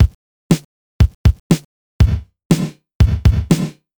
Но как ими например ударные как в AMS RMX16 сделать - наверное придётся дополнительно обрабатывать. Вложения drums.mp3 drums.mp3 159,2 KB · Просмотры: 3.978